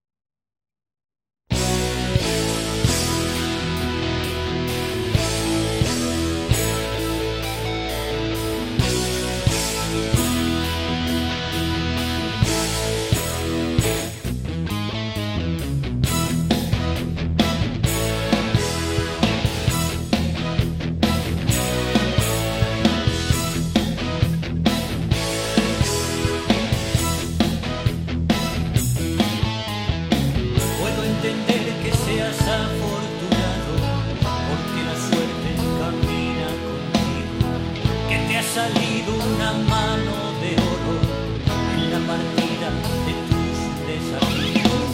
Pop / Rock